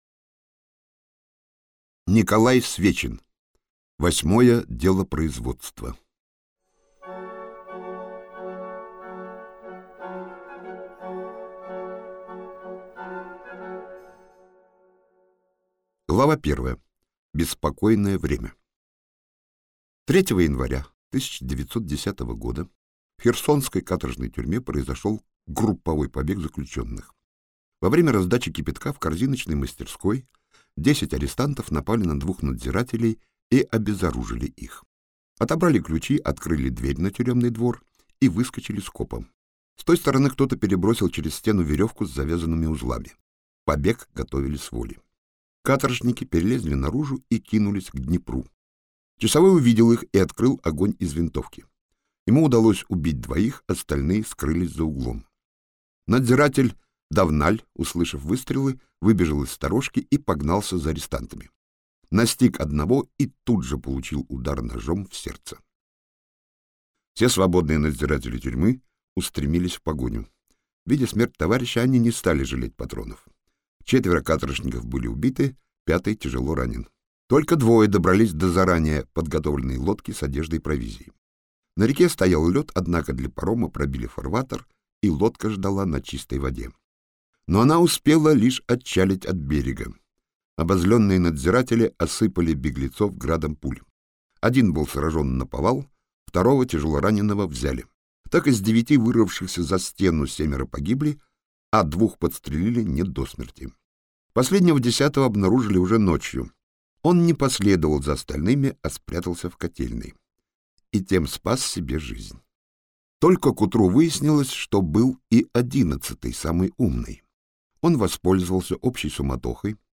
Аудиокнига Восьмое делопроизводство - купить, скачать и слушать онлайн | КнигоПоиск